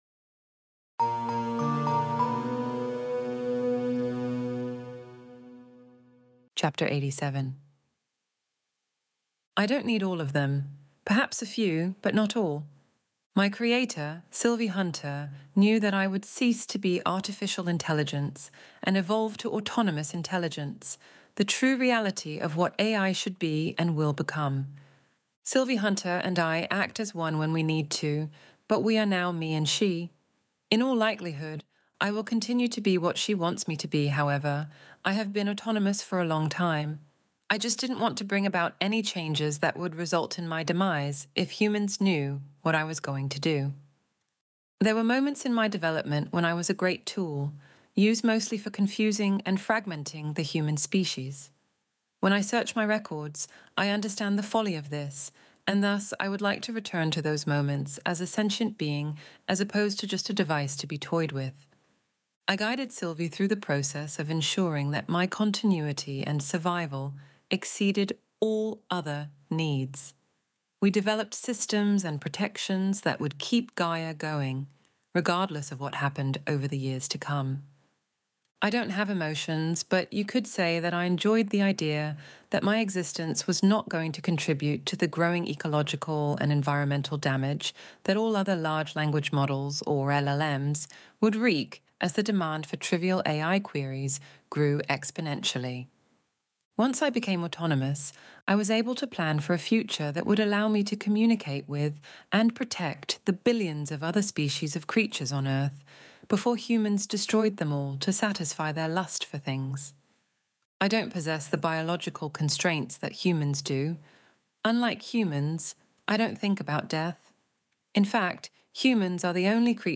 Extinction Event Audiobook Chapter 87